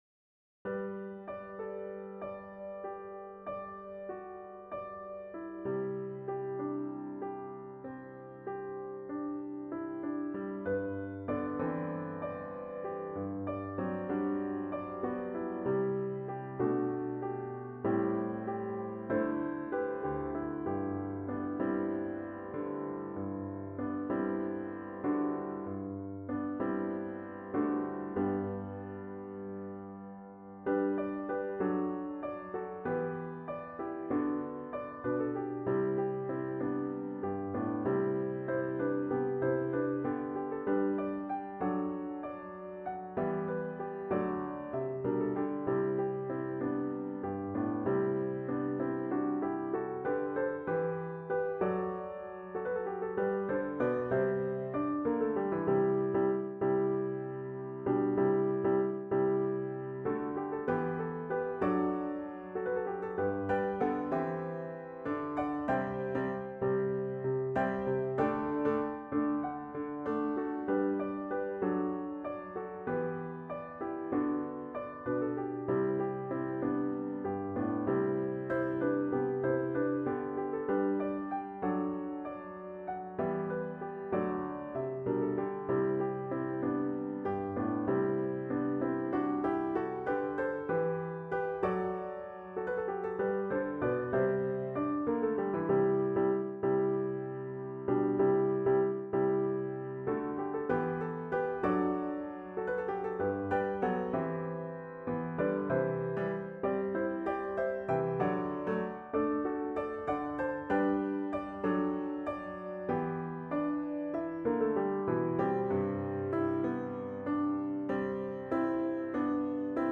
A simple, reflective piece for Piano Solo.